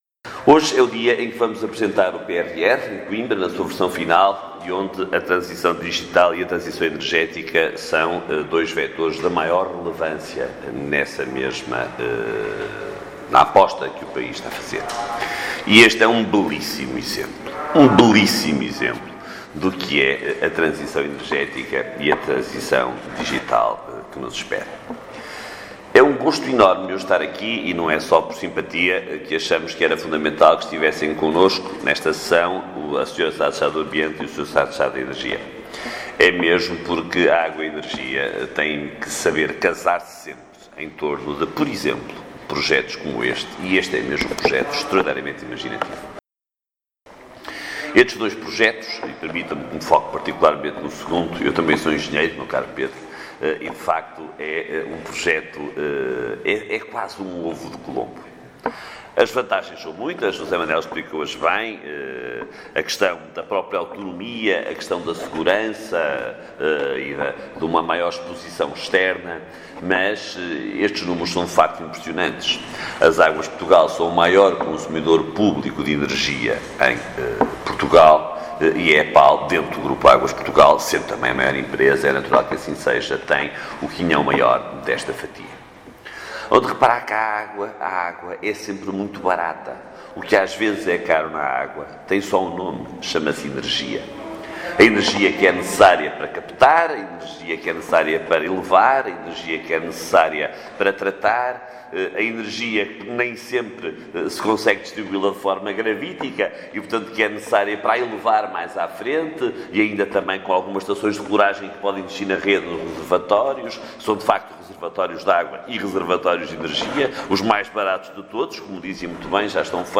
A Estação de Tratamento de Água (ETA) de Asseiceira acolheu a sessão de apresentação pública sobre a reabilitação e ampliação do Sistema de Alenquer IV e sobre a construção de uma mini-central hidroelétrica em Tomar, naquela que é a maior ETA do país.
ÁUDIO | Ministro do Ambiente e da Ação Climática, Matos Fernandes
EPAL-SESSAO-MINISTRO-AMBIENTE.mp3